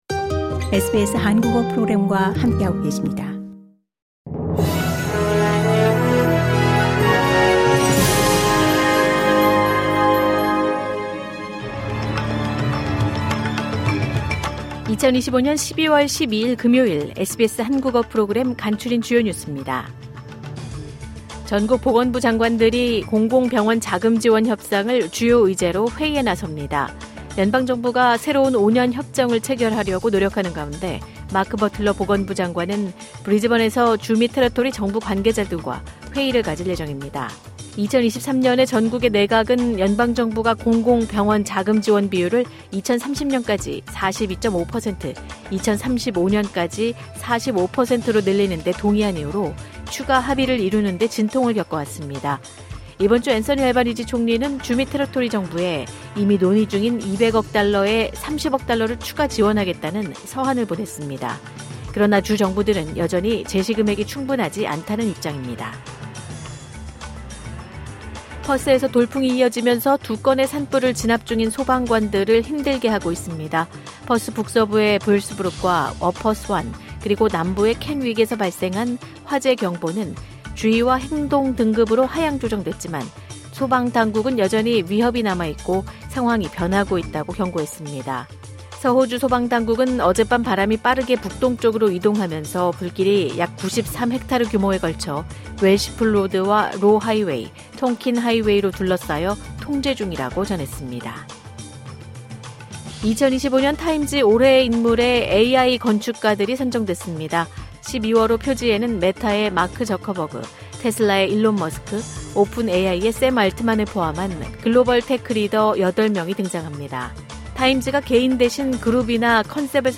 호주 뉴스 3분 브리핑: 2025년 12월 12일 금요일